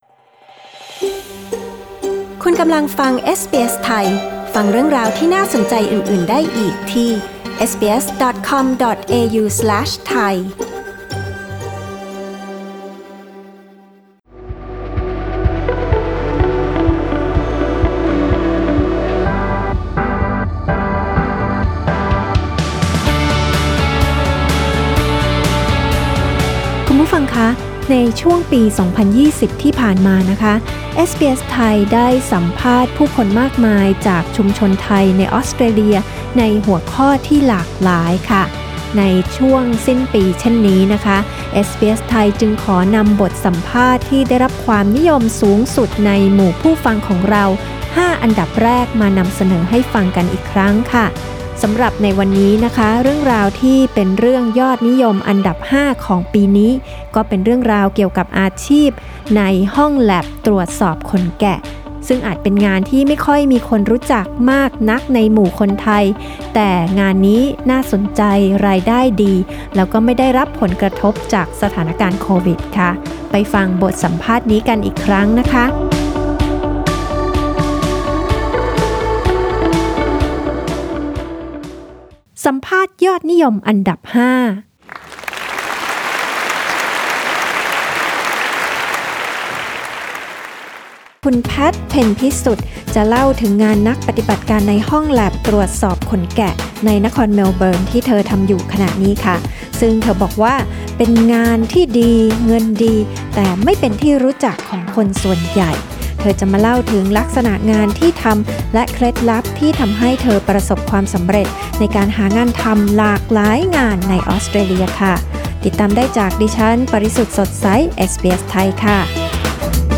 สัมภาษณ์ยอดฮิตติดอันดับ 5 ประจำปี 2020 งานดีเงินดีในห้องแล็บตรวจสอบขนแกะ Source: supplied/SBS Thai